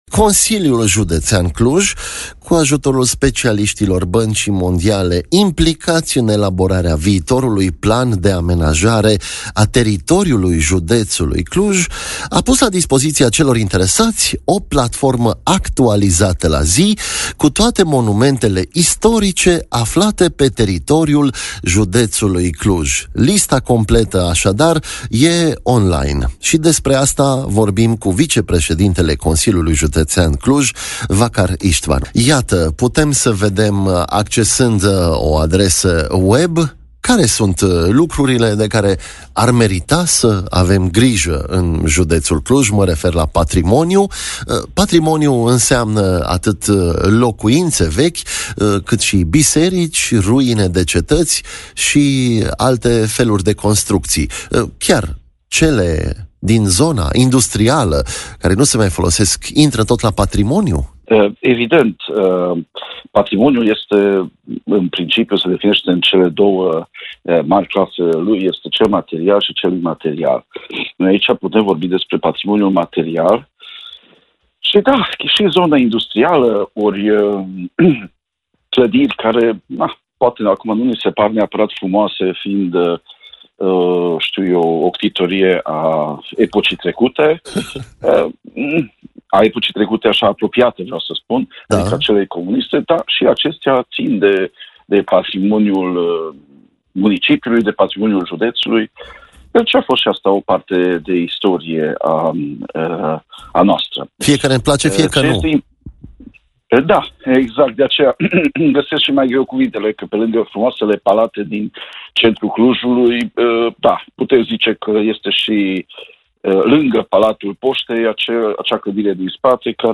Vákár István, vicepreședintele Consiliului Județean Cluj, a vorbit la EBS Radio despre harta în care putem vedea valorile clujene.